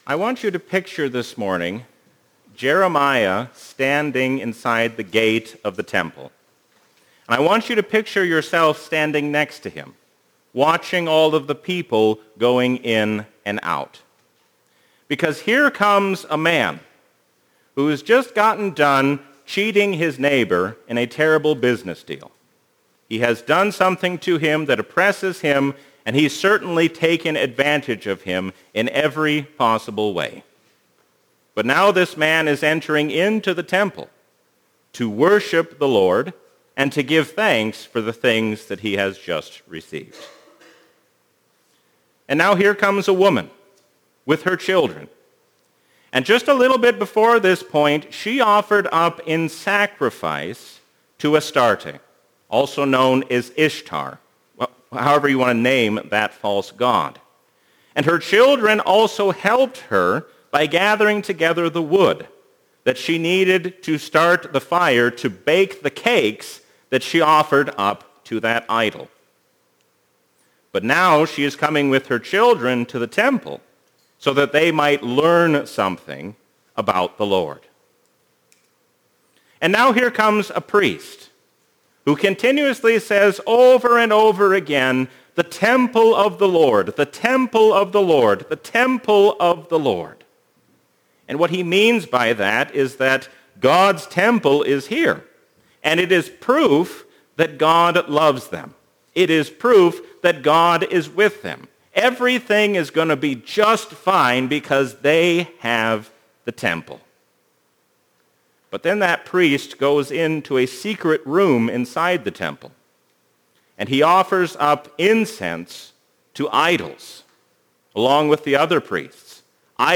A sermon from the season "Trinity 2024." Flee the wrath of God on the Last Day not by turning to yourself, but by calling on the name of Jesus Christ.